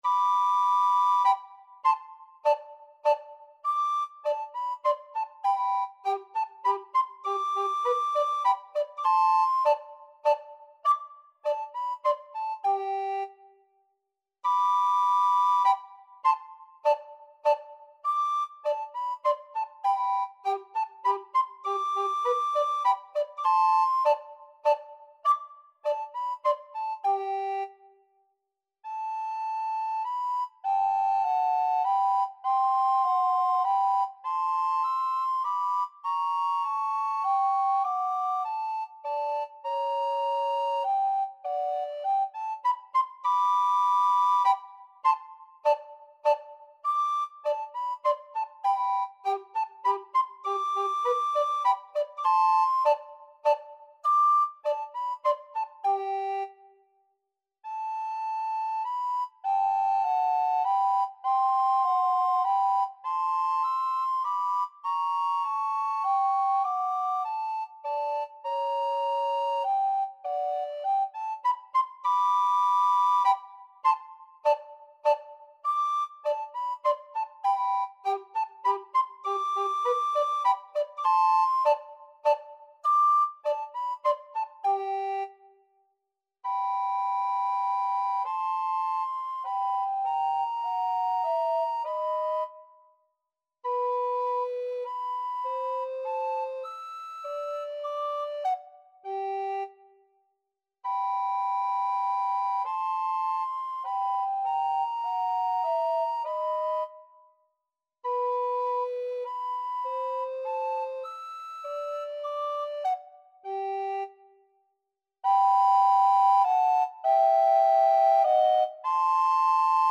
Alto Recorder 1Alto Recorder 2
3/4 (View more 3/4 Music)
Allegretto - Menuetto
Classical (View more Classical Alto Recorder Duet Music)